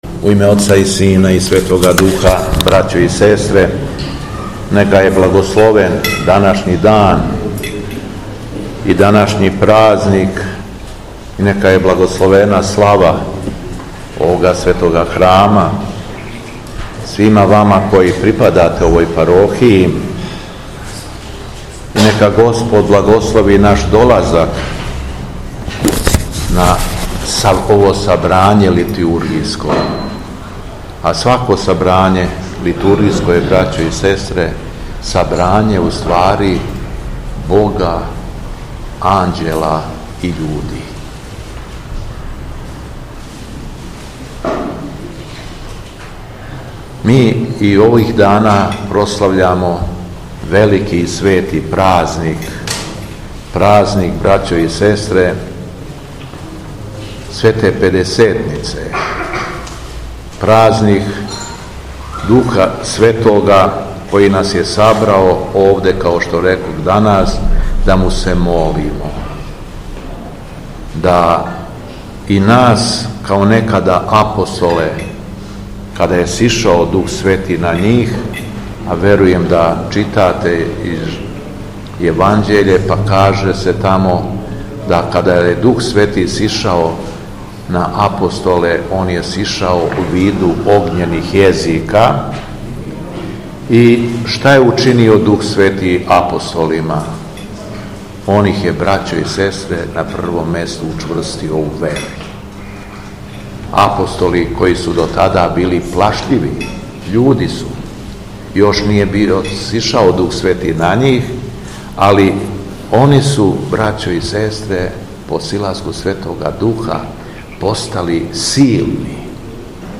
ДУХОВСКИ УТОРАК - СЛАВА ЦРКВЕ СВЕТЕ ТРОЈИЦЕ У КРАГУЈЕВАЧКОМ НАСЕЉУ ЦЕРОВАЦ - Епархија Шумадијска
Беседа Његовог Високопреосвештенства Митрополита шумадијског г. Јована
Након прочитаног јеванђелског зачала, Митрополит Јован је произнео беседу рекавши: